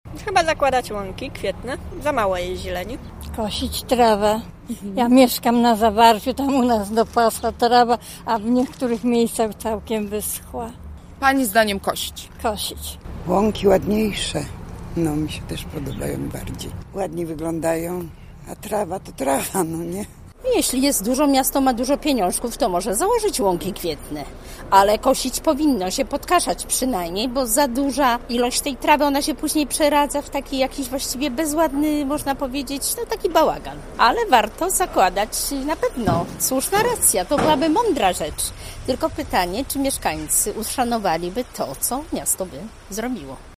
Zapytaliśmy gorzowian, czy miasto powinno kosić trawy czy zakładać łąki kwietne: